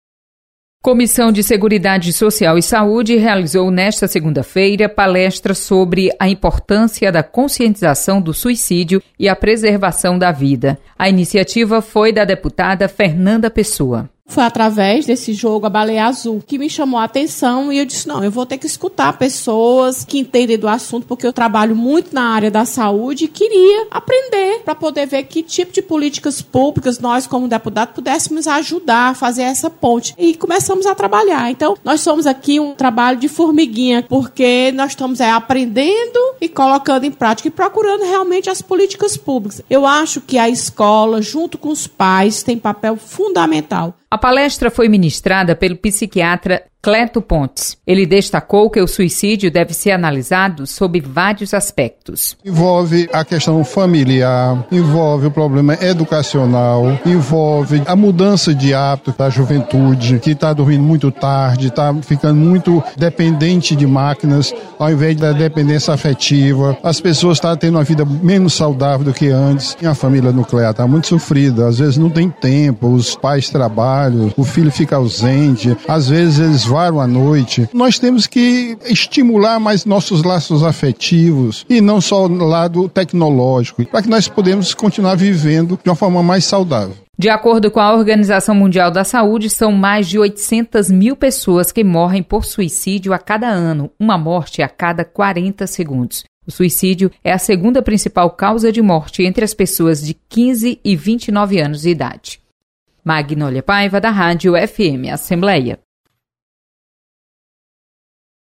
Comissão realiza palestra de conscientização do suicídio. Repórter